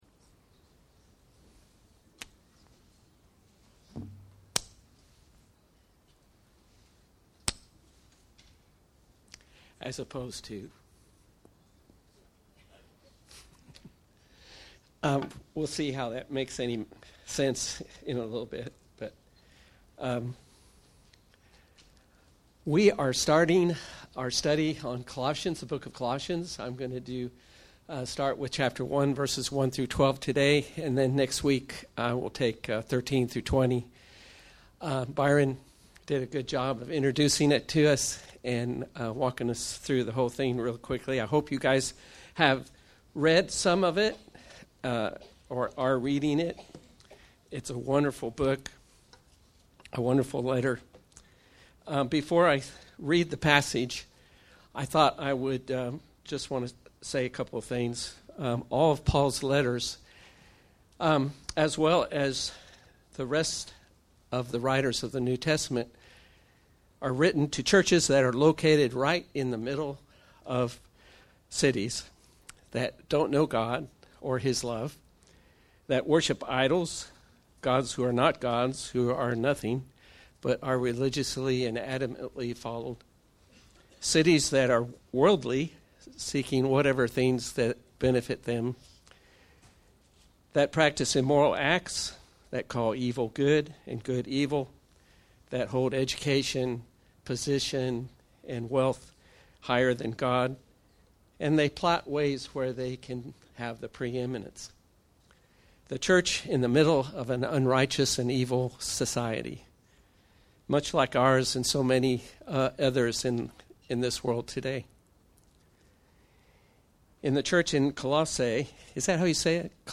Shadow & Substance Passage: Colossians 2:1-12 Service Type: Sunday Morning « Non-Religiosity Christ